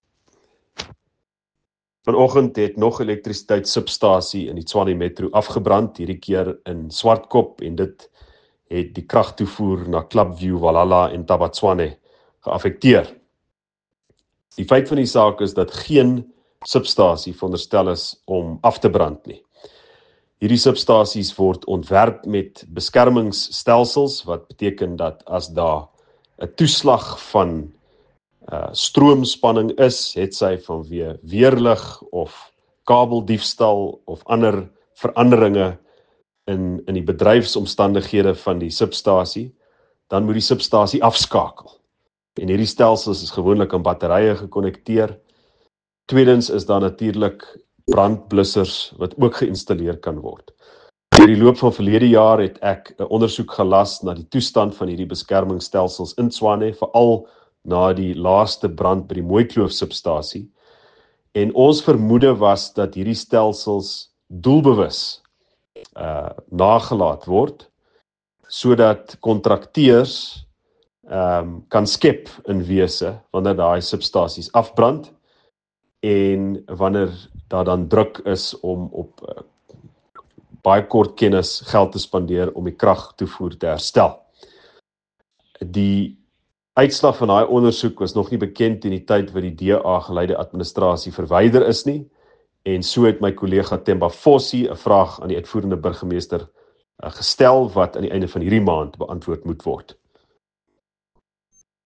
Note to Editors: Please find English and Afrikaans soundbites by Ald Cilliers Brink here, and